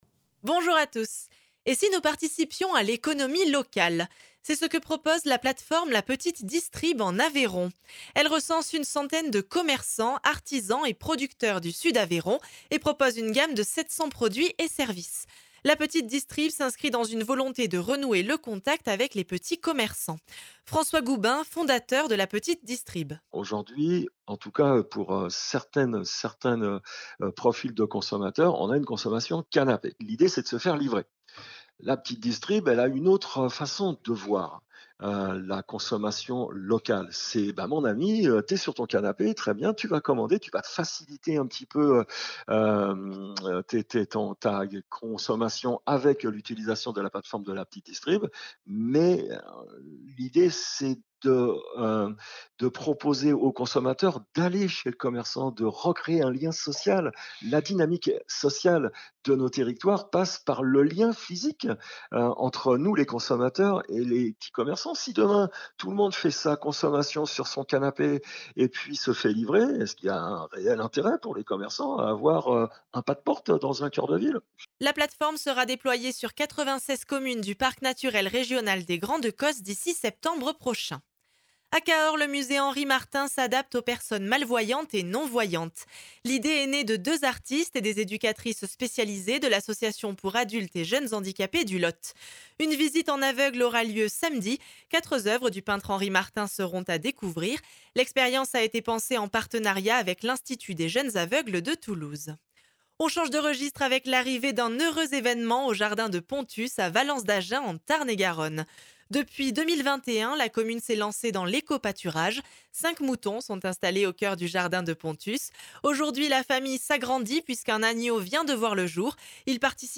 L’essentiel de l’actualité de la région Occitanie en 3 minutes. Une actualité centrée plus particulièrement sur les départements de l’Aveyron, du Lot, du Tarn et du Tarn & Garonne illustrée par les interviews de nos différents services radiophoniques sur le territoire.